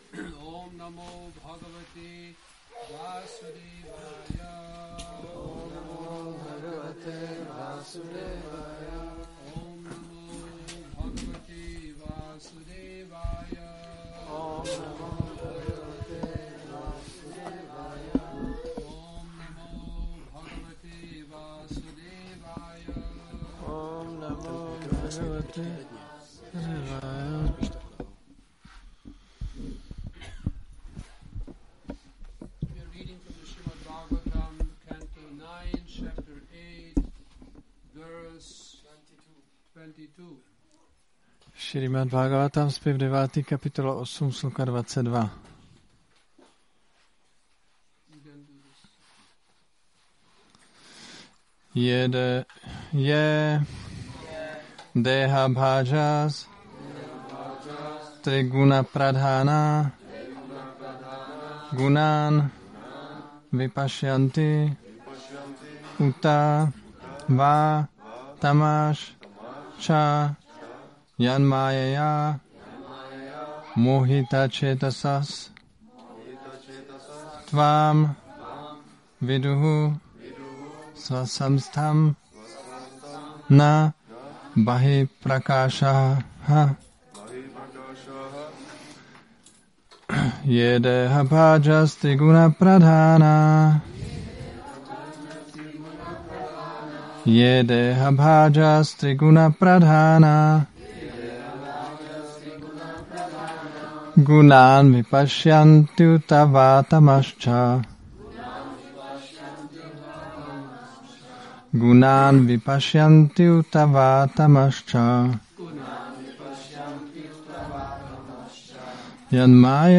Přednáška SB-9.8.22 – Šrí Šrí Nitái Navadvípačandra mandir